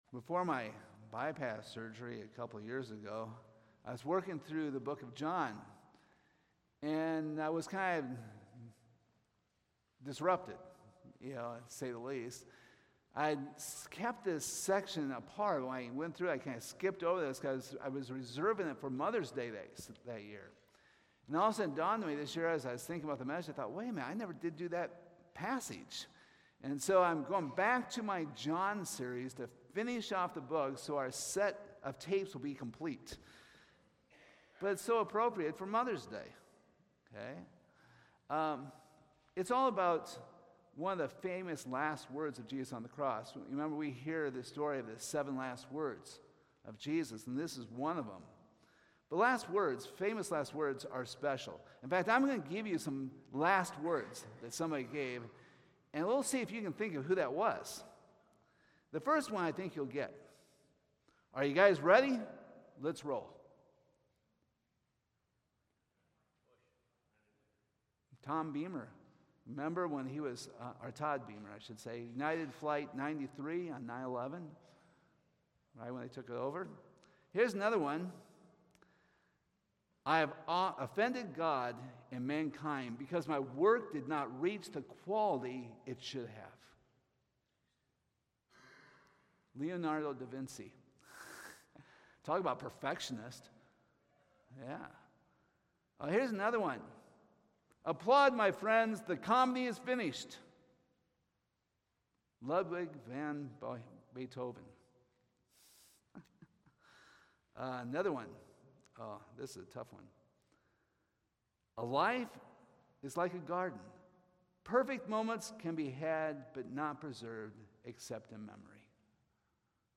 John 19:25-27 Service Type: Sunday Morning We hope you were blessed and challenged by the ministry of Calvary Baptist Church.